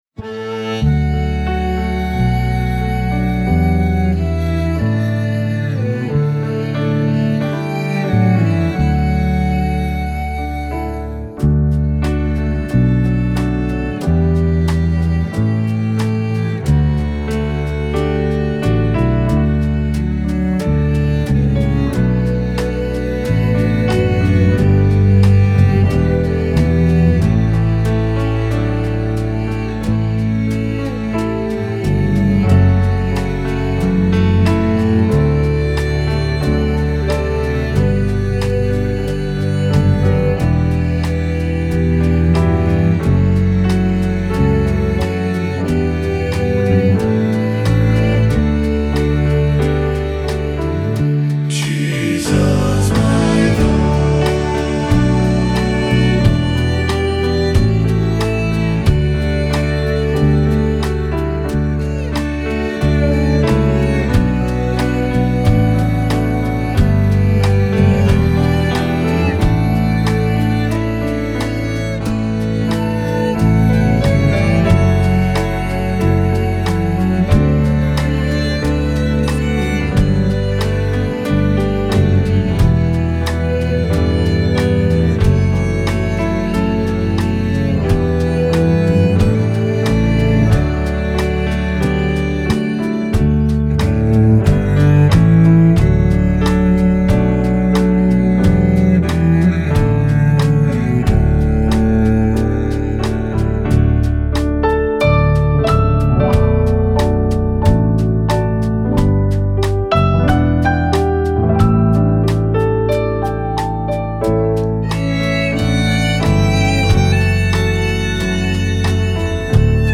Performance Track